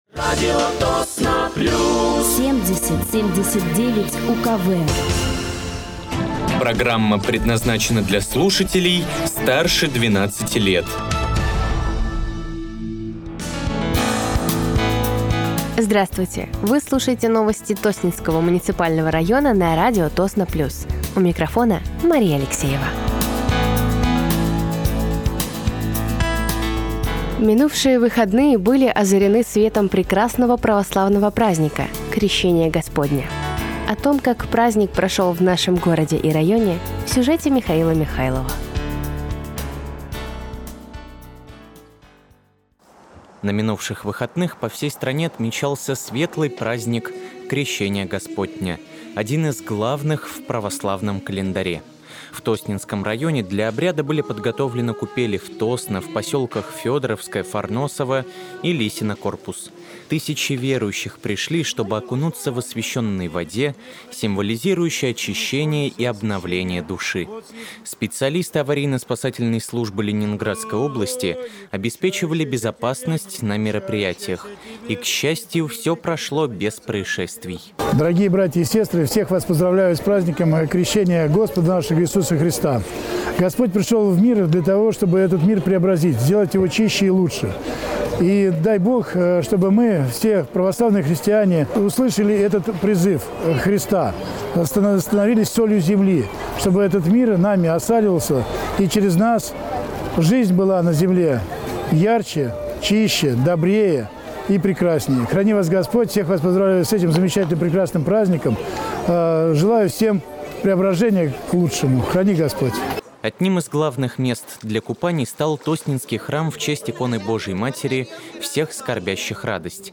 Новости Тосненского района от 23.01.2025
Вы слушаете новости Тосненского муниципального района на радиоканале «Радио Тосно плюс».